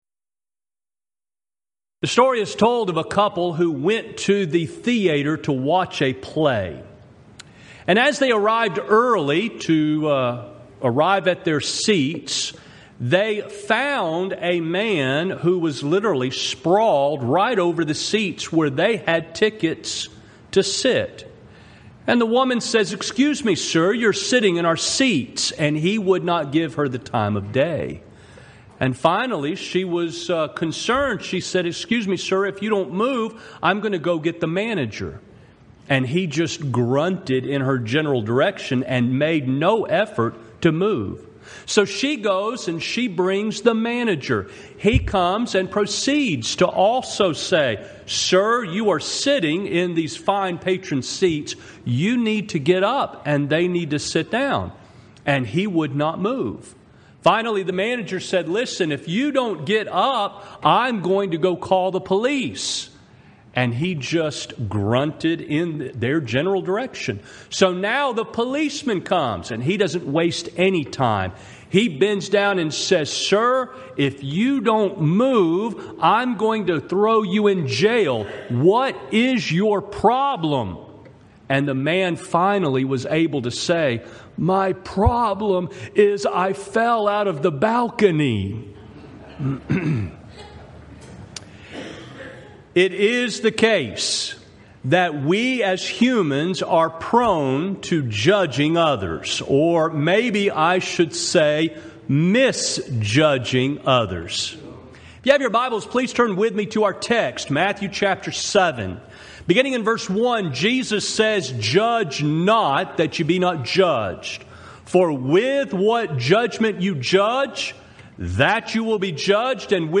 Event: 2015 South Texas Lectures Theme/Title: The Truth About Religious Teachings